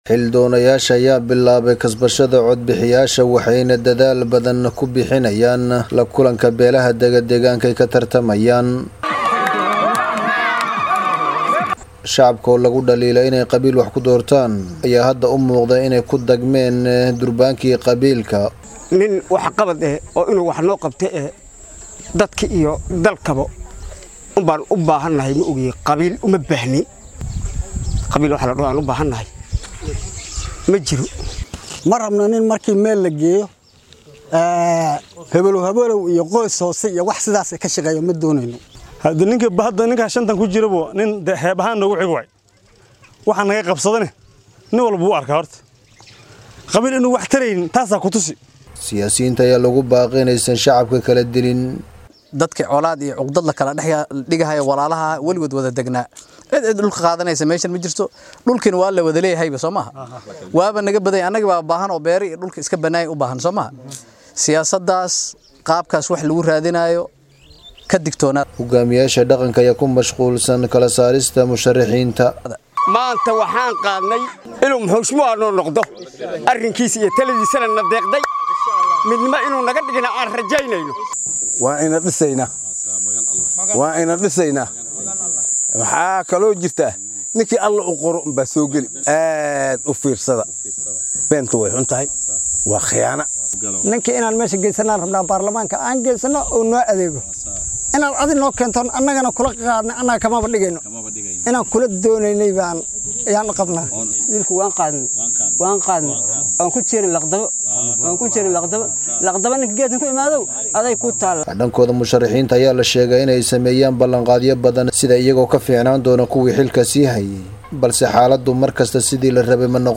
Hasa ahaate qaar ka mid ah dadweynaha deegaanka Dadaab oo u warramay warbaahinta Star ayaa sheegaya inaysan qofka xil doonka ah ku dooran doonin qabiil balse waxqabadkiisa.